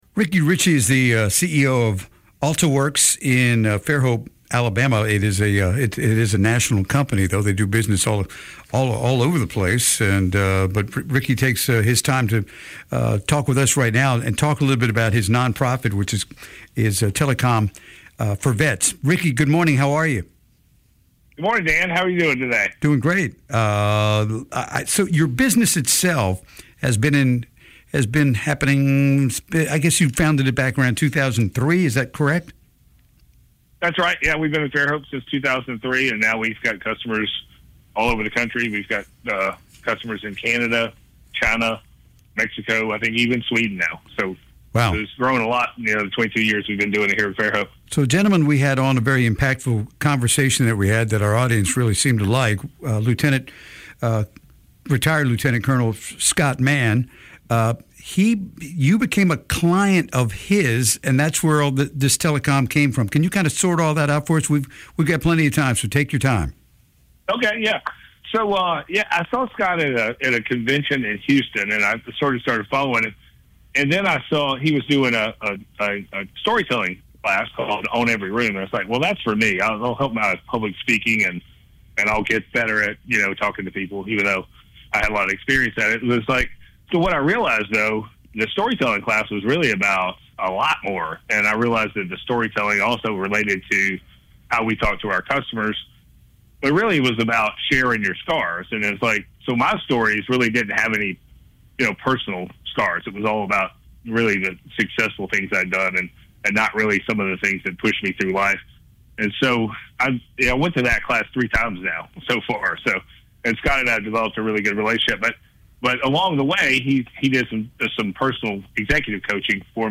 Mobile Mornings interviews